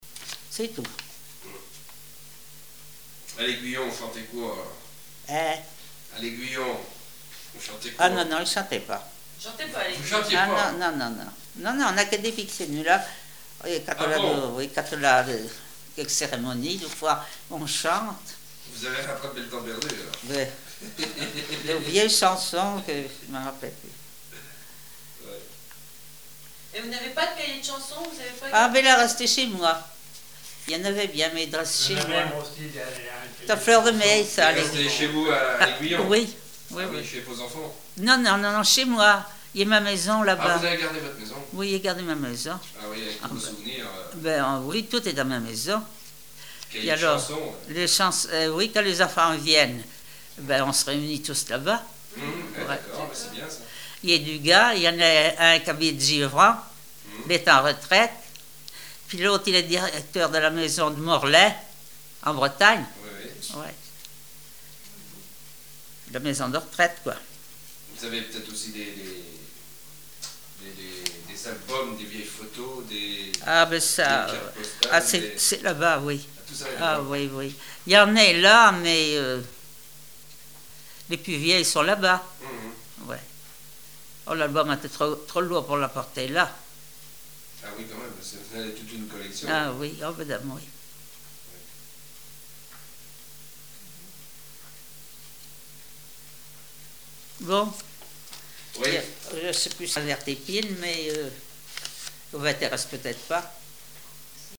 chansons traditionnelles et témoignages
Catégorie Témoignage